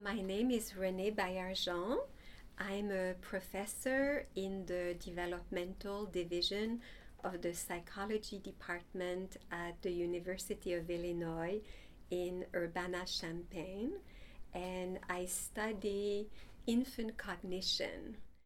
Dr. Baillargeon introduces herself: